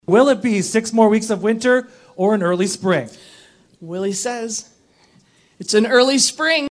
And Mayor Janice Jackson delivered the good news on his behalf.